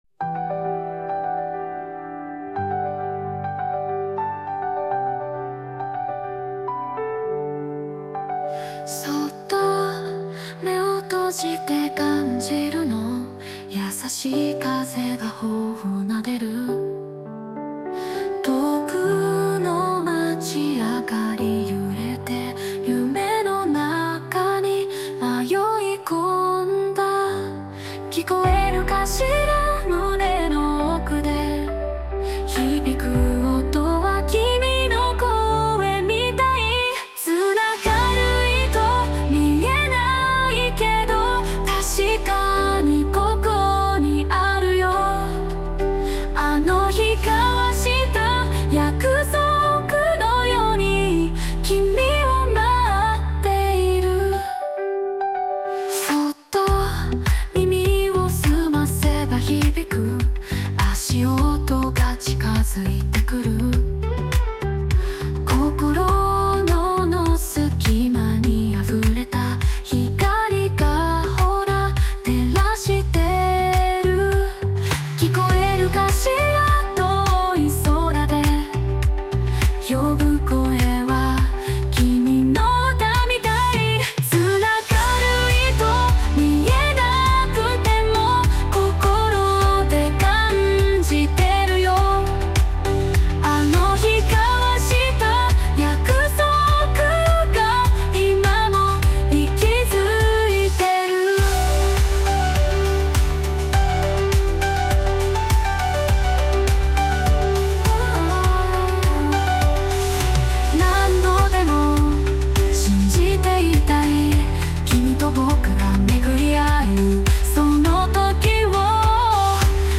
女性ボーカル邦楽 女性ボーカル
著作権フリーオリジナルBGMです。
女性ボーカル（邦楽・日本語）曲です。
ある大好きな映画のテーマ曲をイメージして作りましたが、思ったより切ない曲に仕上がりました。